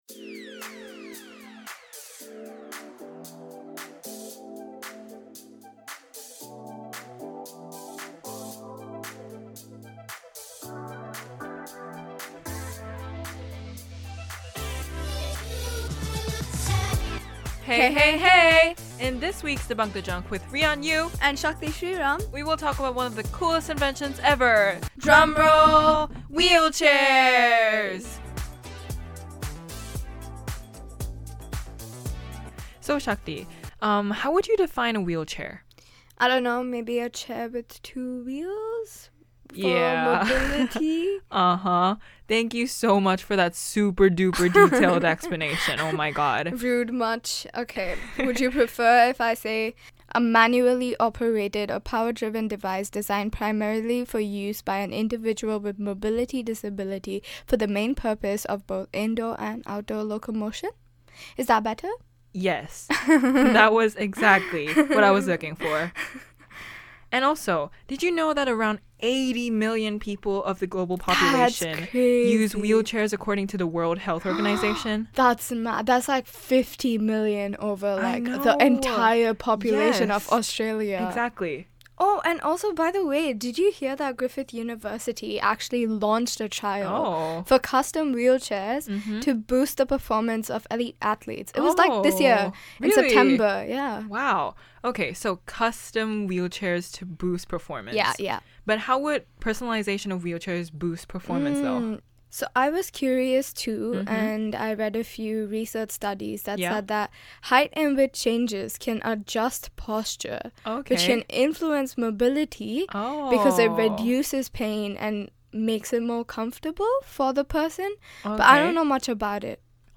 Drawing on diverse perspectives and experiences, the questions and answers were carefully selected from the interviews to offer a surface-level glimpse into these complex dynamics.